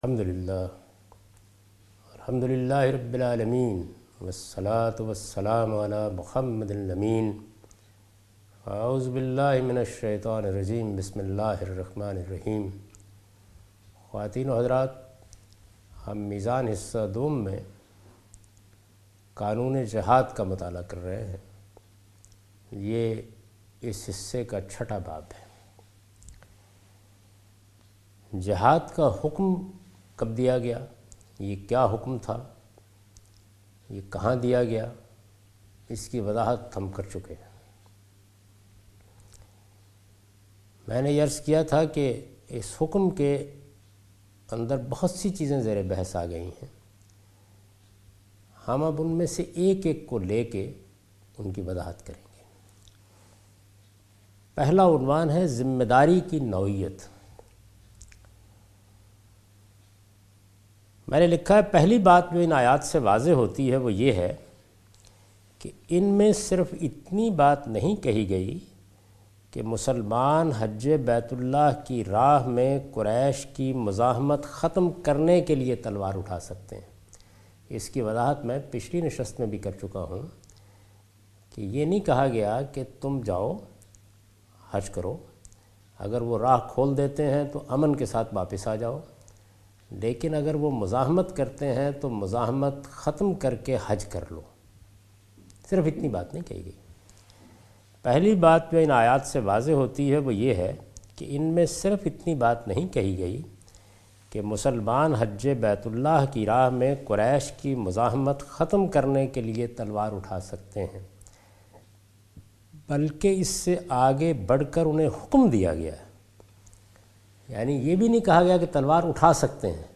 A comprehensive course taught by Javed Ahmed Ghamidi on his book Meezan. In this lecture series he will teach The Shari'ah of Jiahd. The Directive of Jihad is discussed in this lecture. In this sitting nature of obligation is explained in the context of Jihad.